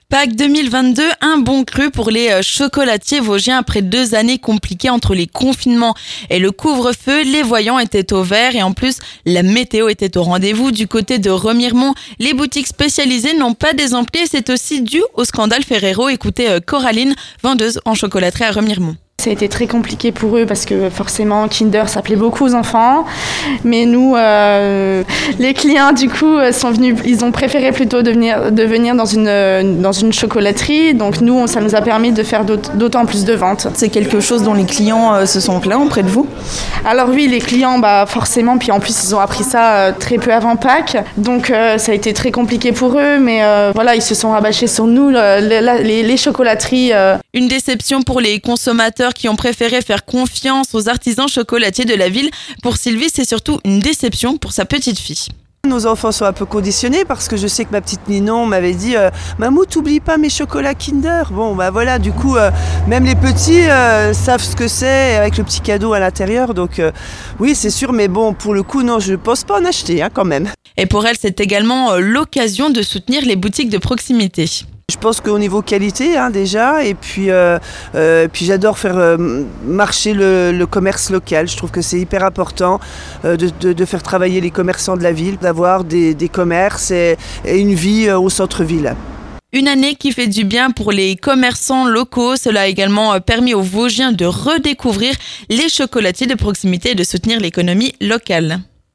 Dimanche matin, quelques heures avant la fermeture des chocolateries, Vosges FM s'est rendu à Remiremont pour en discuter avec les commerçants et les consommateurs.